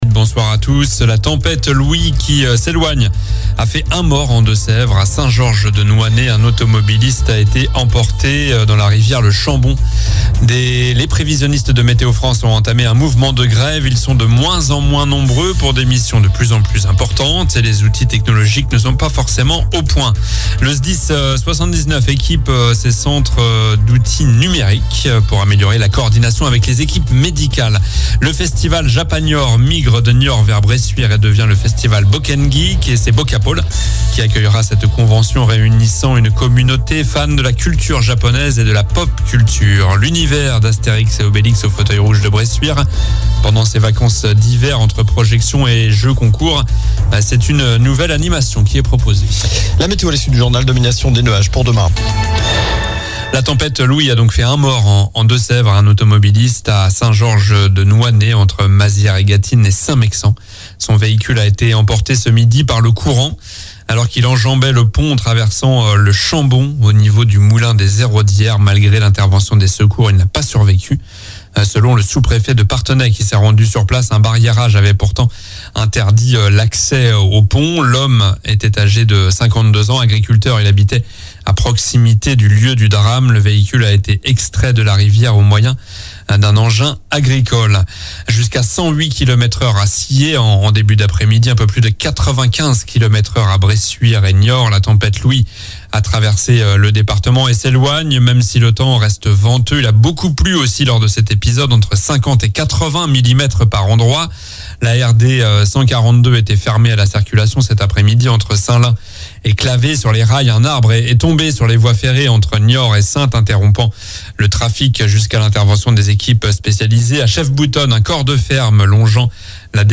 Journal du jeudi 22 février (soir)